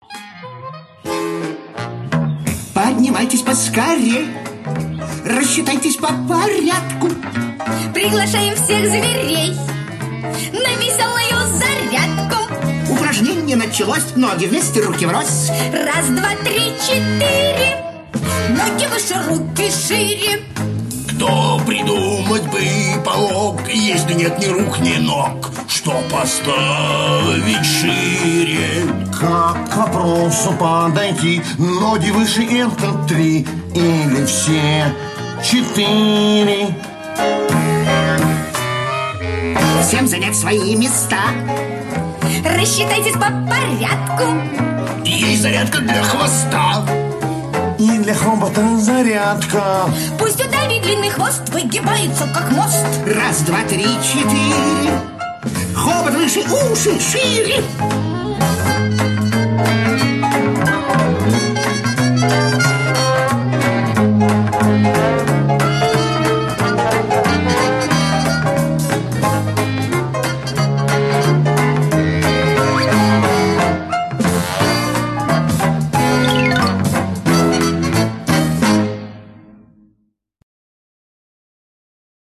Так и хочется двигаться, выполняя физические упражнения.